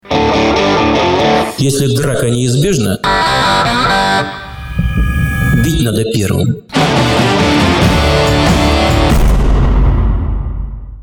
громкие
мощные
электрогитара
качающие
агрессивные
рок